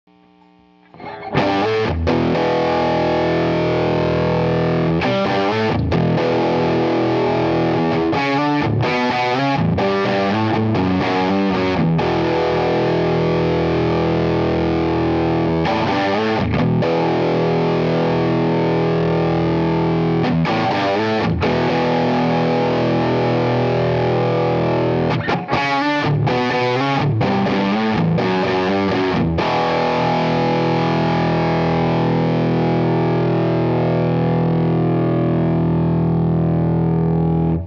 This set has a sweet and fat low end that is strong and punchy with just the perfect balance of lower mids.  The top end is smooth and has a great presence that balances great with the solid mid range.